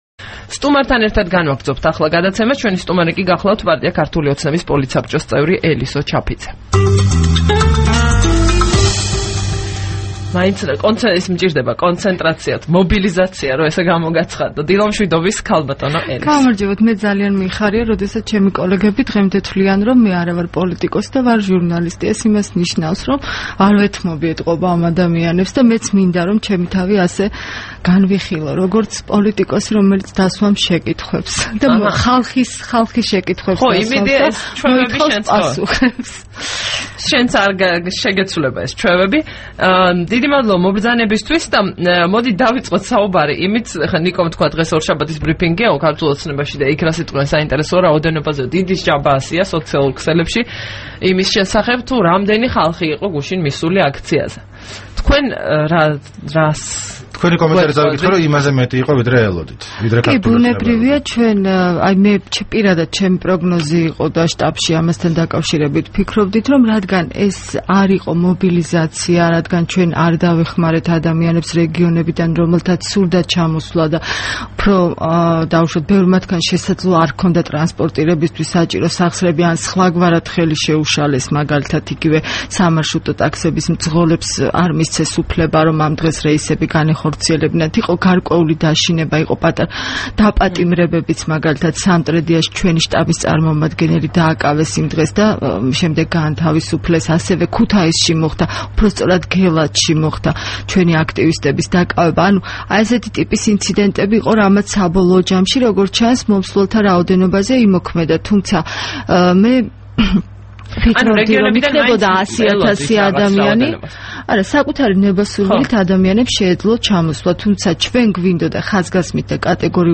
საუბარი ელისო ჩაფიძესთან